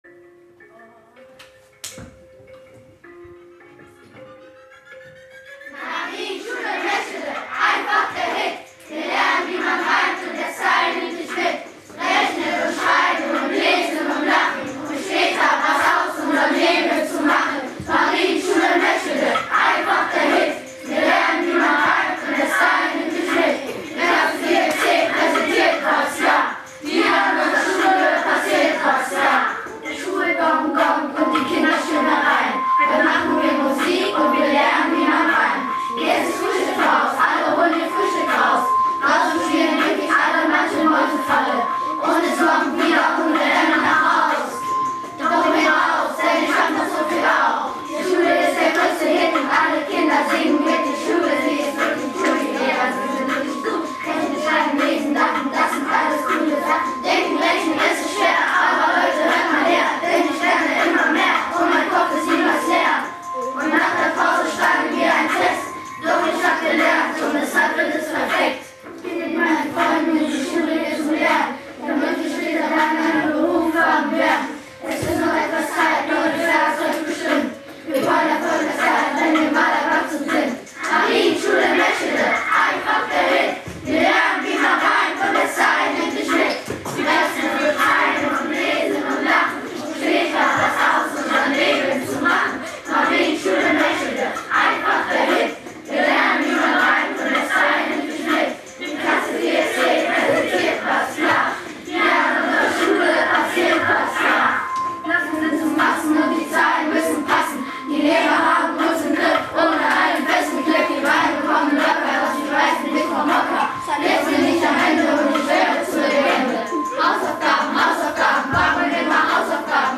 Zum Nachhören: Marienschule Meschede schreibt Rap
rap-der-marienschule.mp3